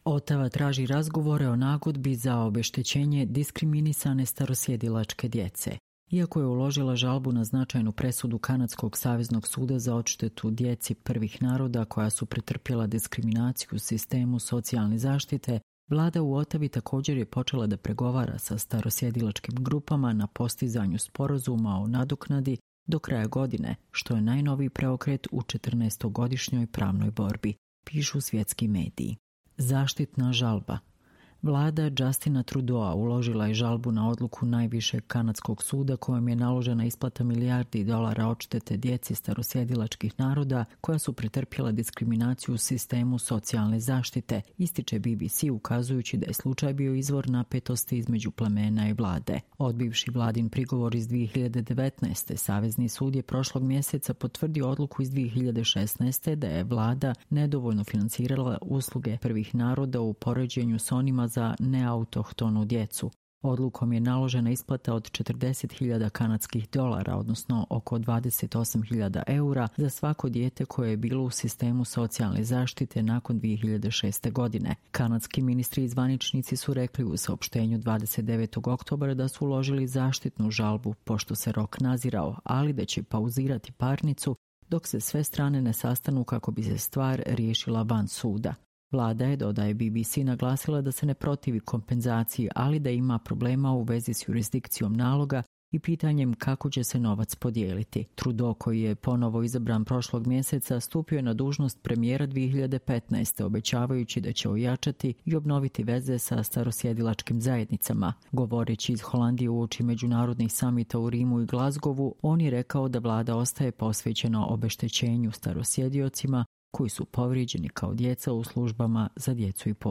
Čitamo vam: Otava traži razgovore o nagodbi za obeštećenje diskriminisane starosjedilačke djece